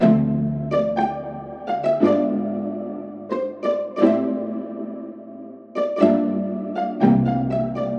描述：Sounds kinda Fake.
标签： 120 bpm Hip Hop Loops Harp Loops 1.35 MB wav Key : Unknown
声道立体声